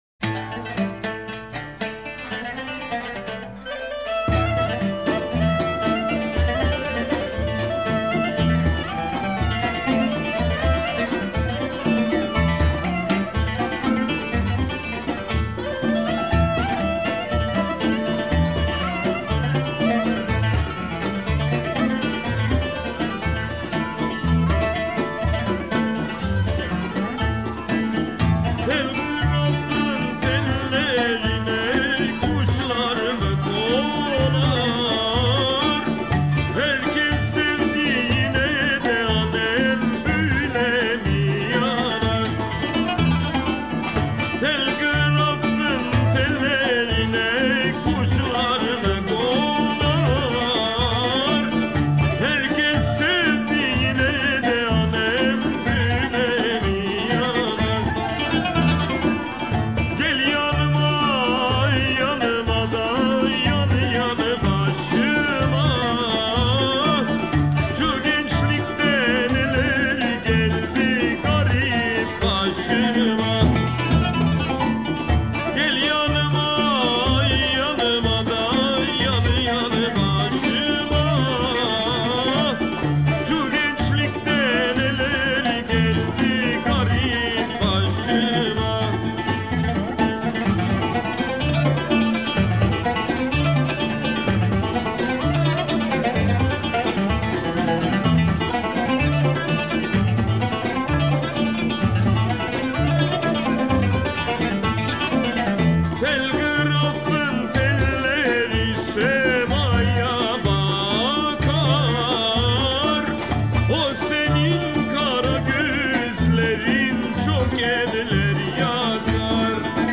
Трек размещён в разделе Русские песни / Армянские песни.